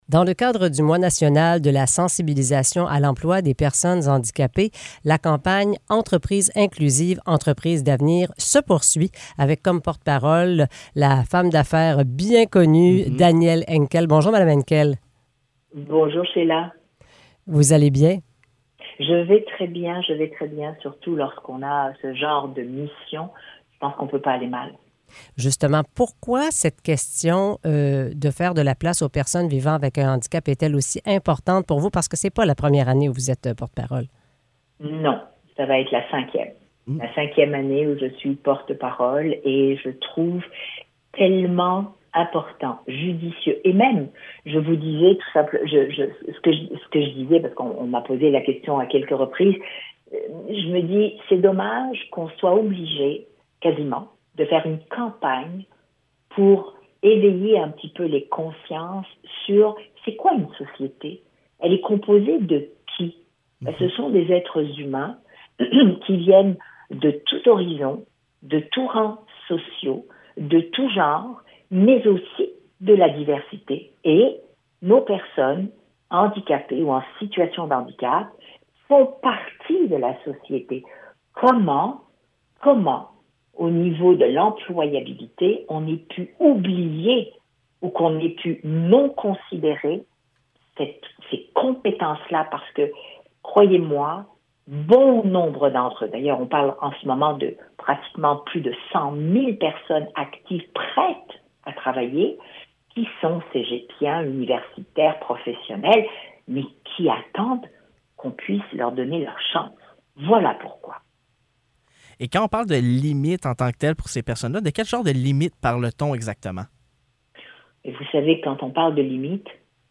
Entrevue avec Danièle Henkel